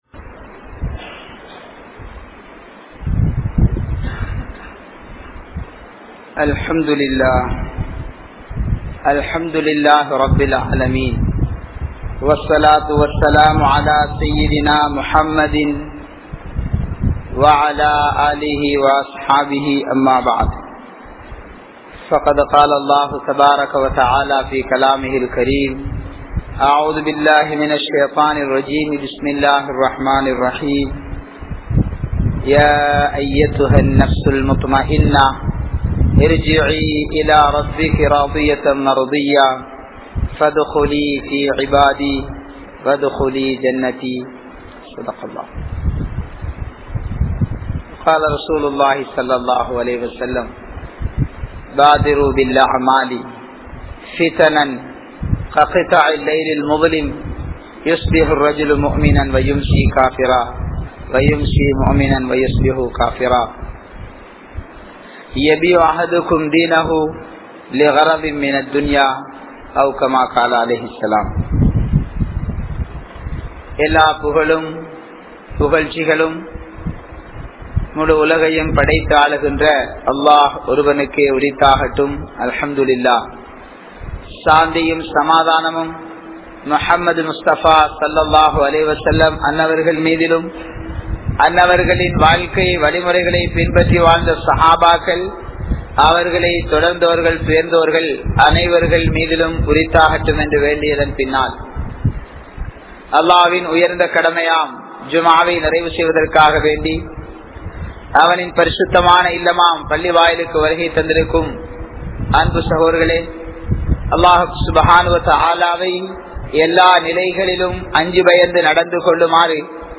Allah`vin Anpu | Audio Bayans | All Ceylon Muslim Youth Community | Addalaichenai